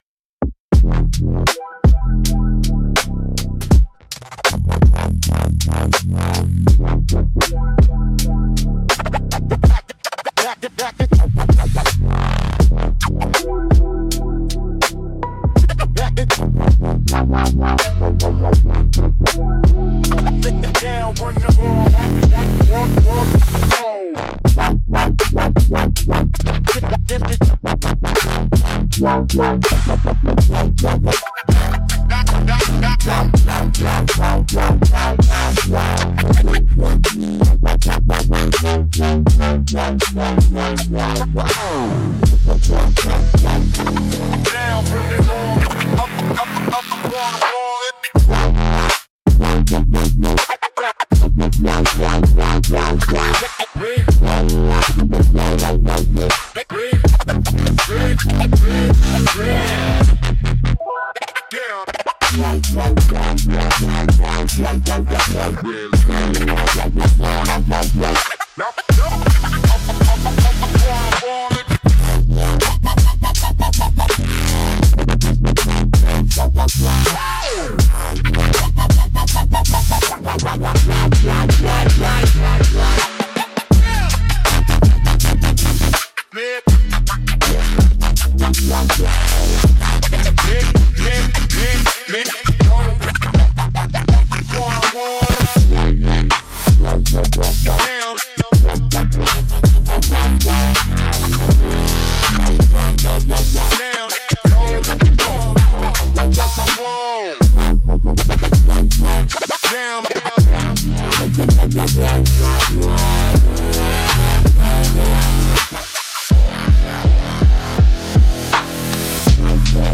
Instrumental - Reverse Gospel